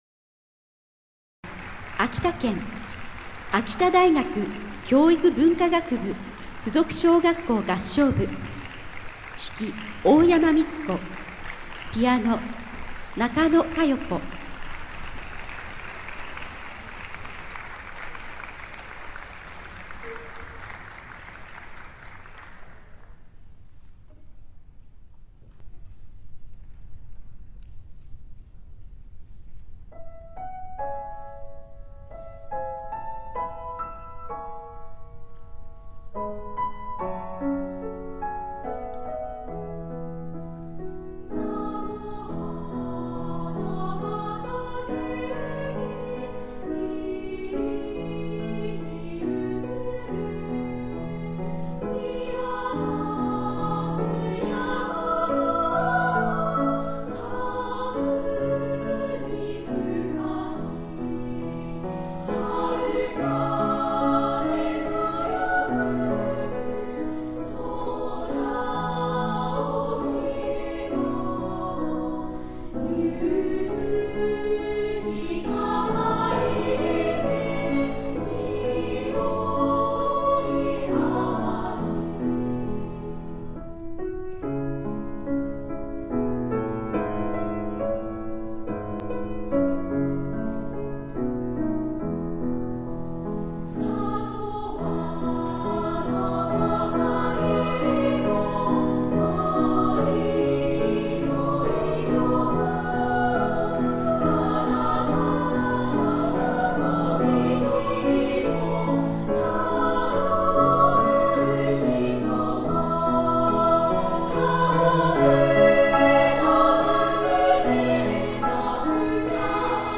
心と心をつなぐコンサート《合唱部》chorus club
11/17 全日本合唱コンクール全国大会（小学生部門）  けんしん郡山文化センター
【課題曲】